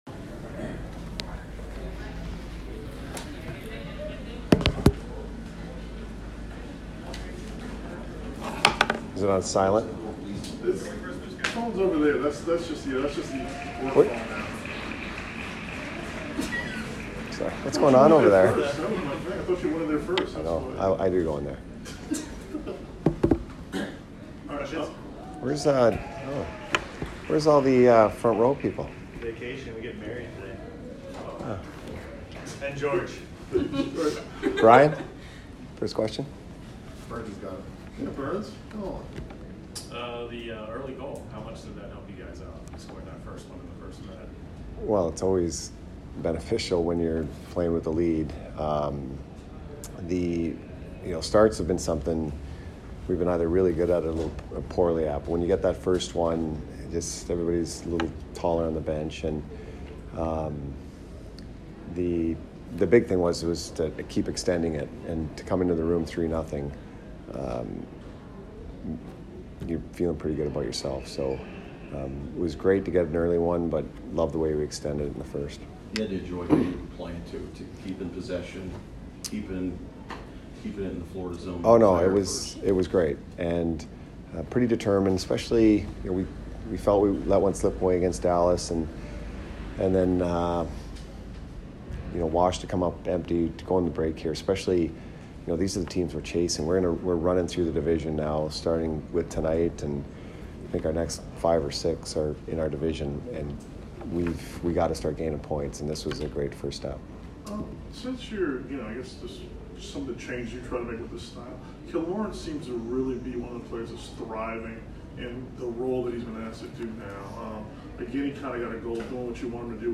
Cooper post-game 12/23